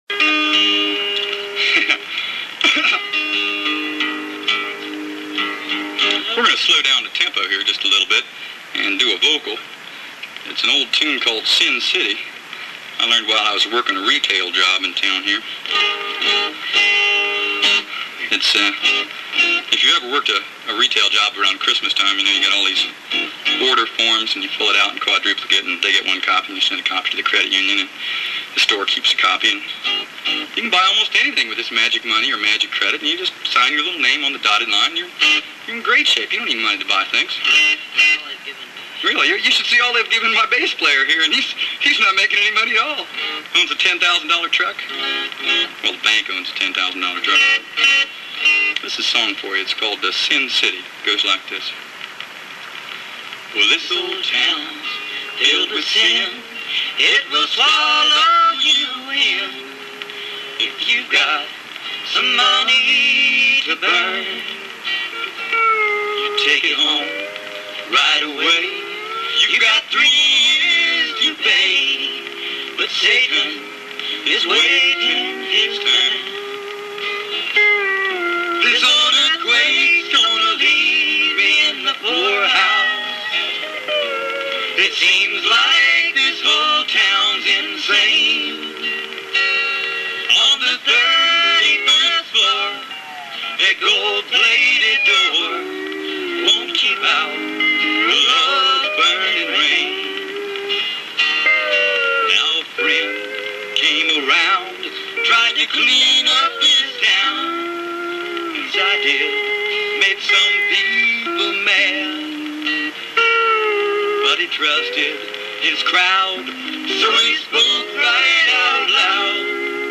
our local college public radio station